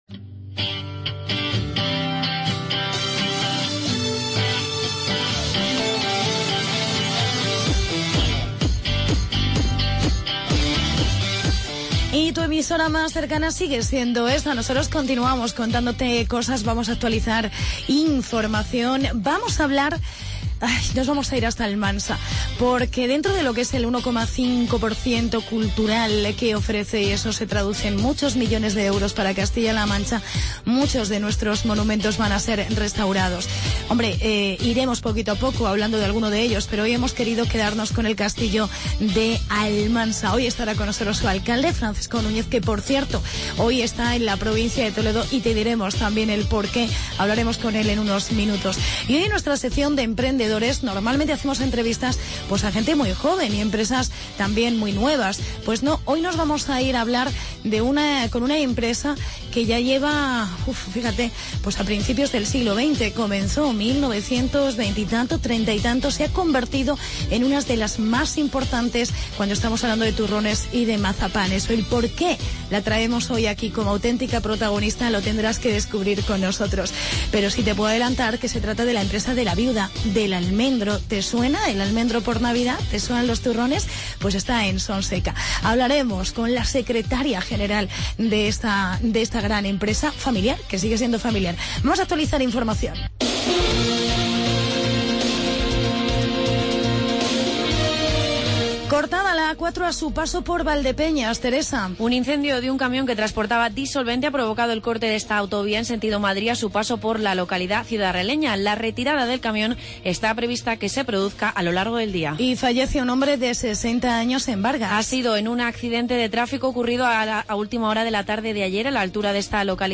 Hablamos con el viceportavoz del PP en las Cortes y Alcalde de Almansa, Francisco Núñez